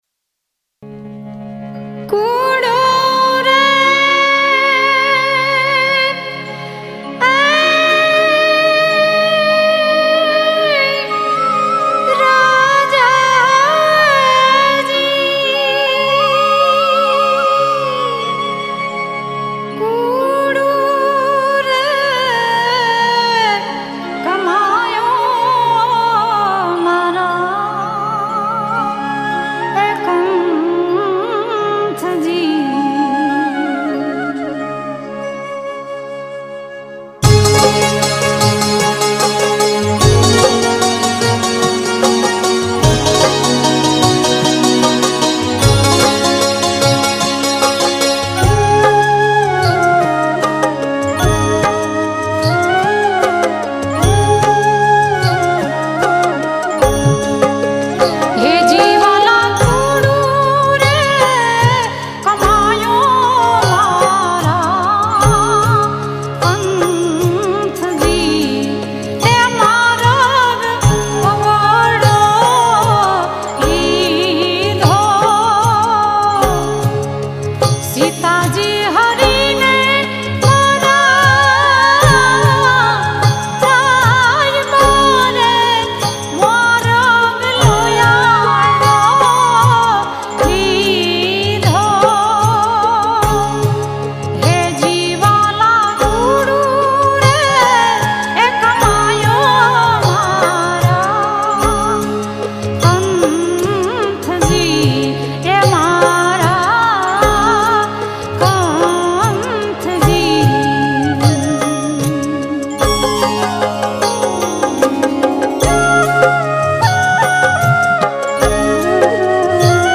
Prabhatiya - Bhajan Gujarati